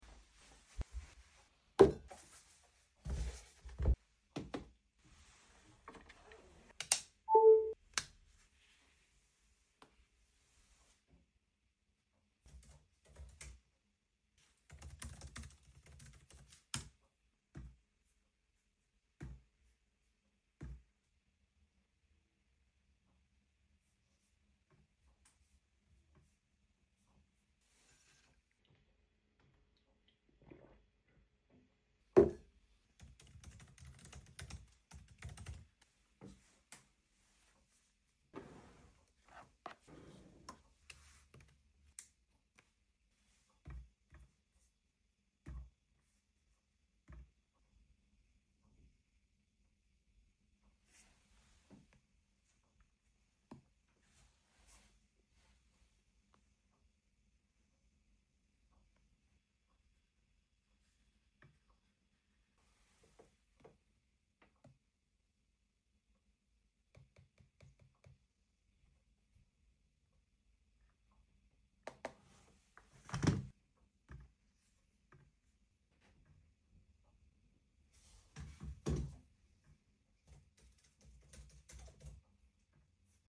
Home office sounds 💻 sound effects free download